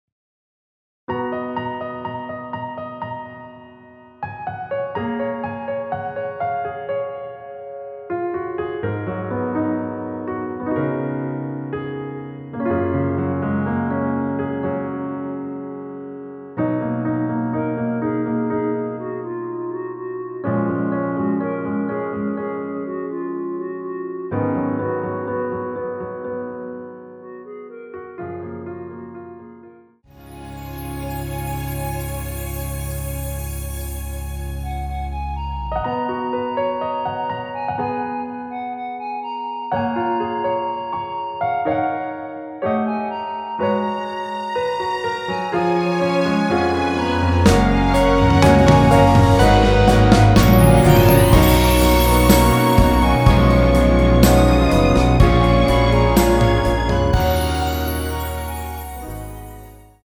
원키에서(-2)내린 멜로디 포함된 MR입니다.(미리듣기 확인)
F#
앞부분30초, 뒷부분30초씩 편집해서 올려 드리고 있습니다.
중간에 음이 끈어지고 다시 나오는 이유는